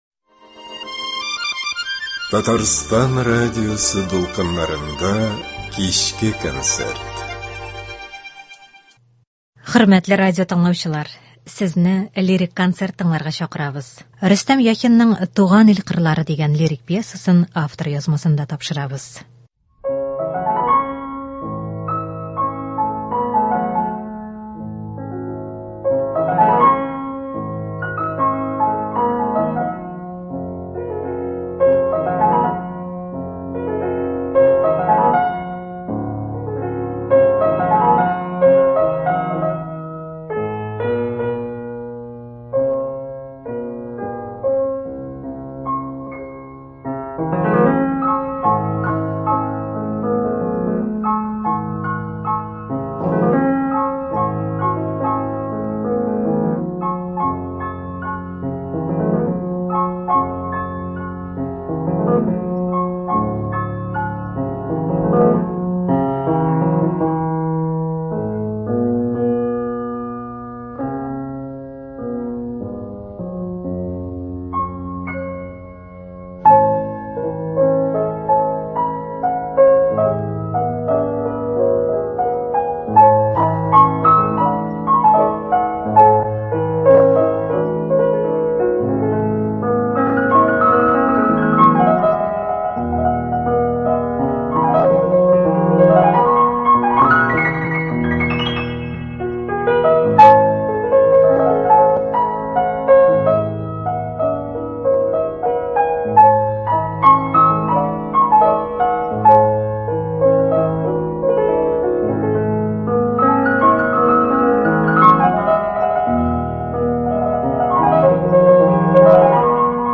Лирик музыка концерты.